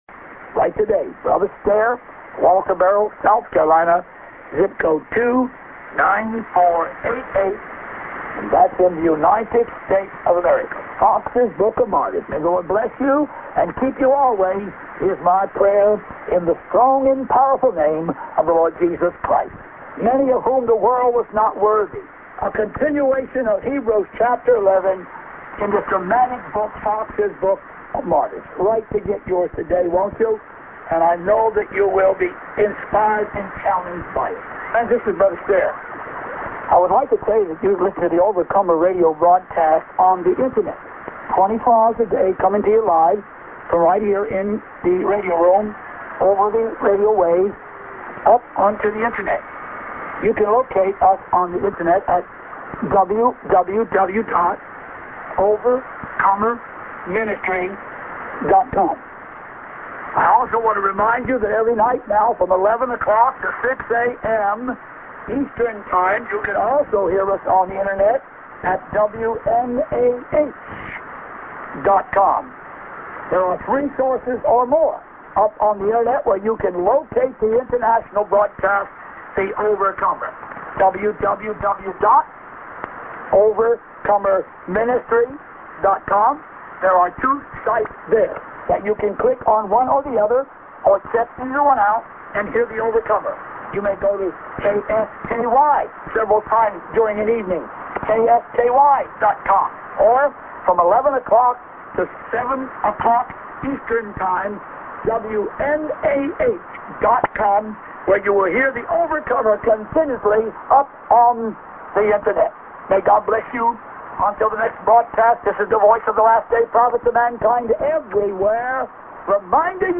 End prog->ID+Web ADDR(man)-> s/off